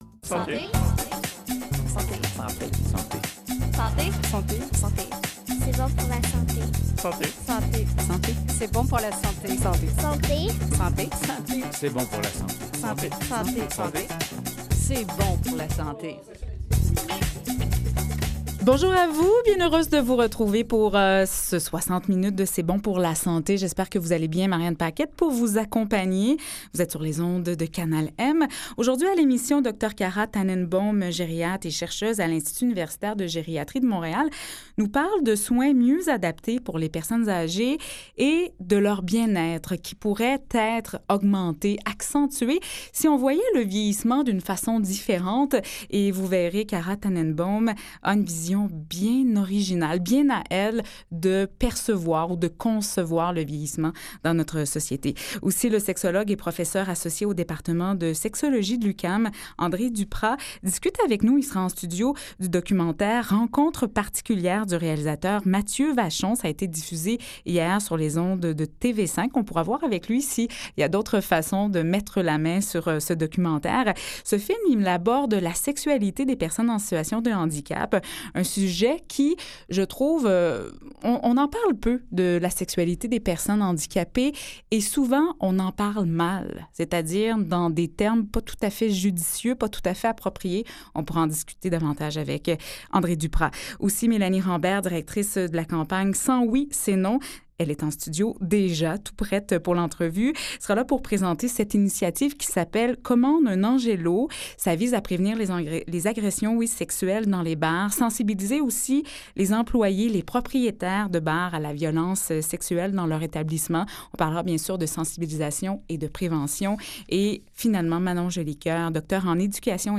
Le magazine santé de Canal M suit de près l'actualité de la santé partout au pays et dans le monde.